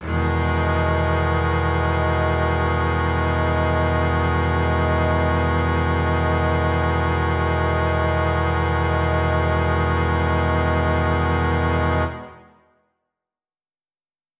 SO_KTron-Cello-Emaj7.wav